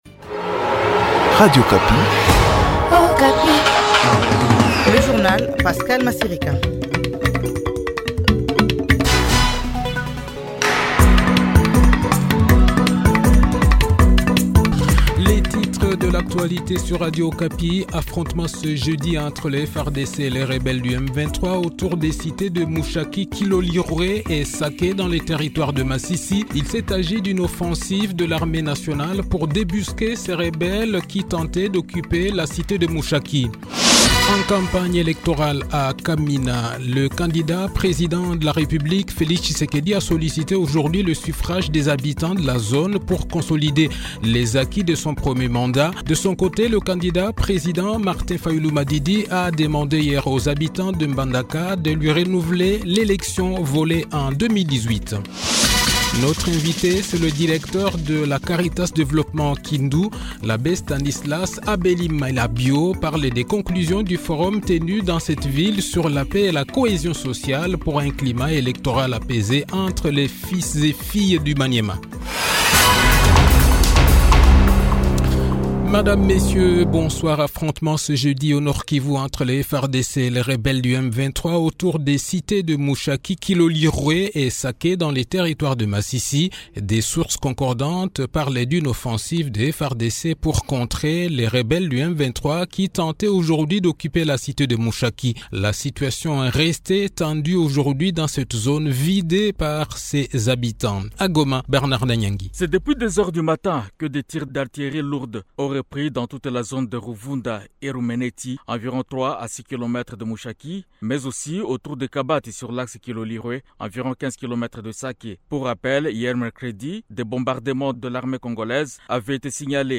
Journal Soir
Le journal de 18 h, 7 Decembre 2023